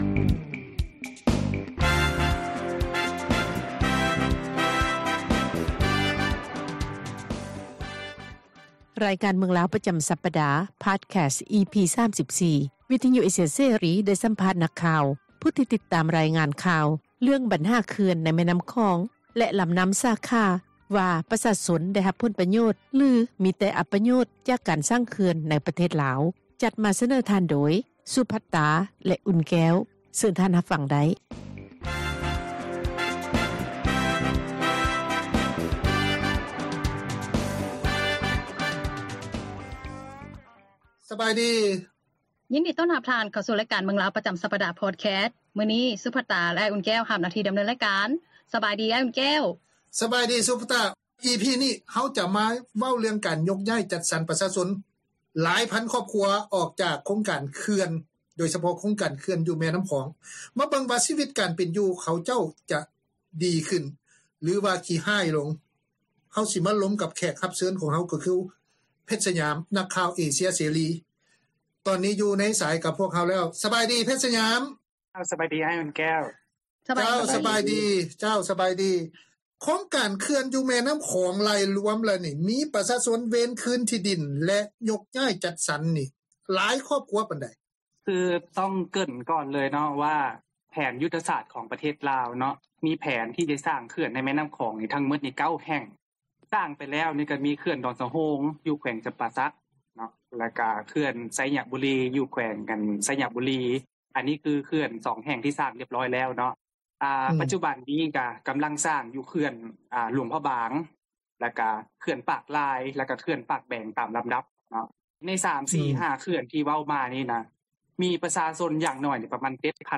ສໍາພາດ ນັກຂ່າວ ຜູ້ທີ່ຕິດຕາມ ແລະລາຍງານຂ່າວ ເລື່ອງບັນຫາ ເຂື່ອນໃນແມ່ນໍ້າຂອງ ແລະ ລໍານໍ້າສາຂາ ວ່າ ປະຊາຊົນ ໄດ້ຮັບຜົນປະໂຫຍດ ຫຼື ມີແຕ່ ອັບປະໂຫຍດ ຈາກ ການສ້າງເຂື່ອນ ໃນປະເທດລາວ.